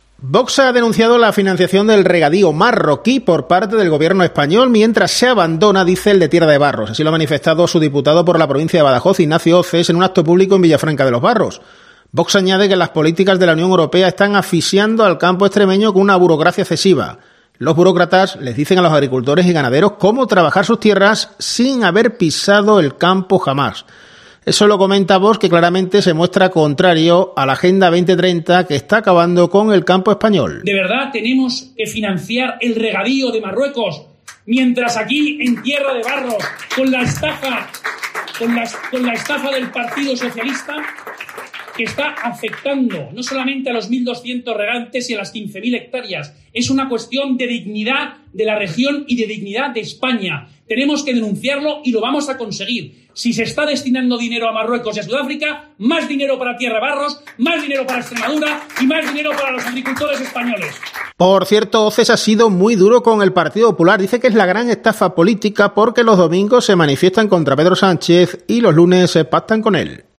Así lo ha manifestado su diputado por la provincia de Badajoz, Ignacio Hoces, en un acto público en Villafranca de los Barros.